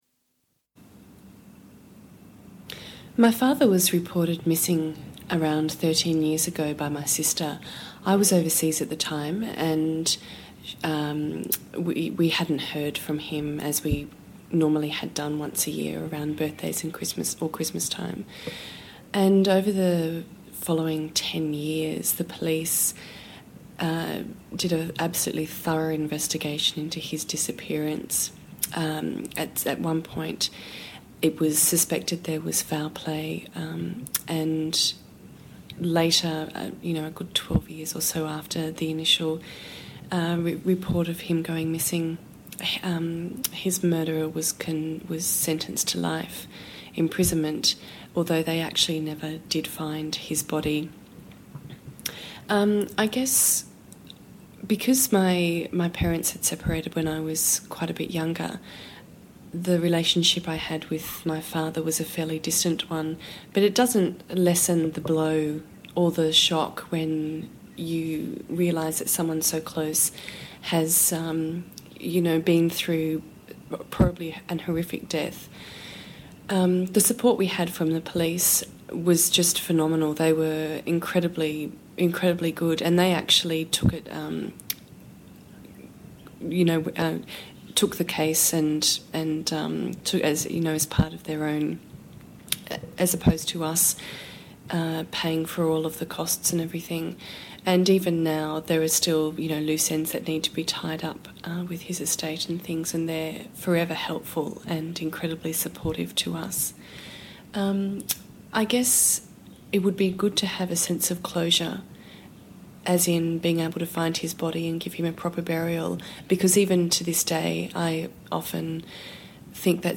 I asked each participant to say whatever they wanted to say about the homicide and chose to play their words raw and unedited.